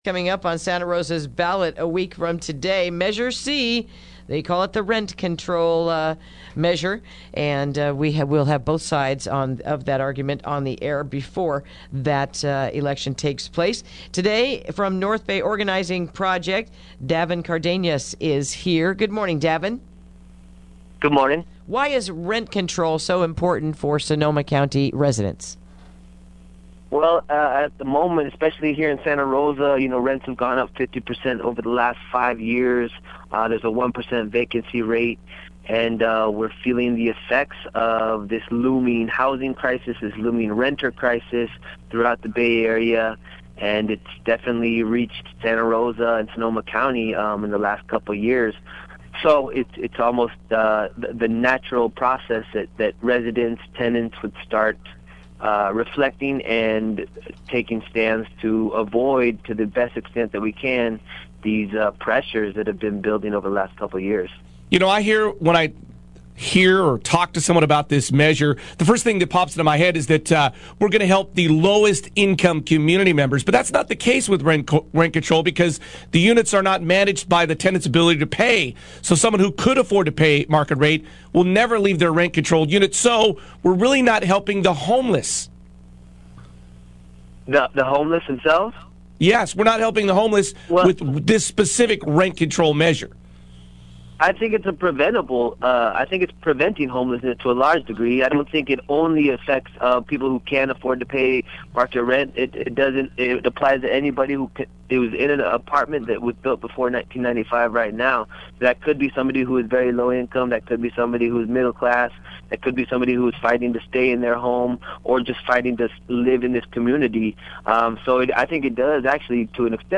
Interview: Measure C is Up to the Voters in One Week